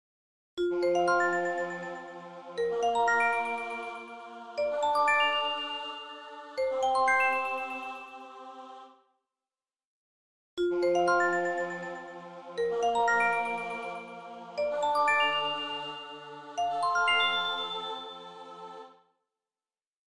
• Качество: 128, Stereo
добрые